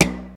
6. 06. Percussive FX 05 ZG